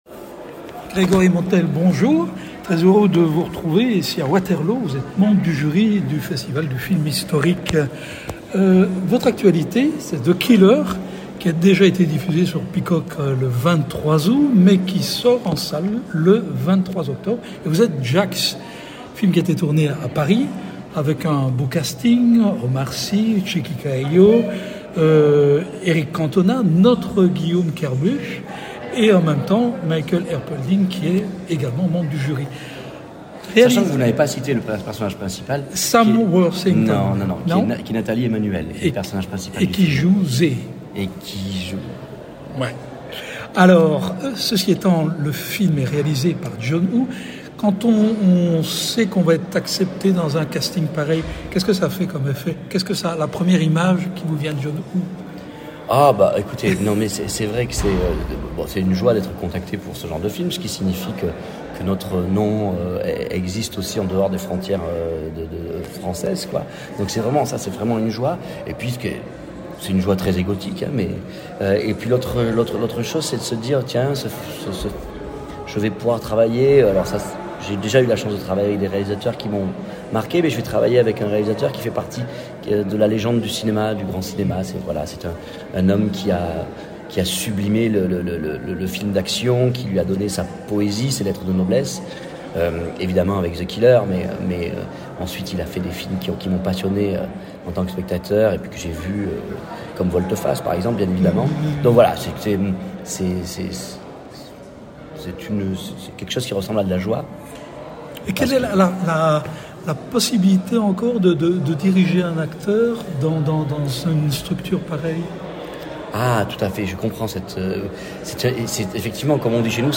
Rencontre avec l’un des membres du jury de la 12e édition du Wahff.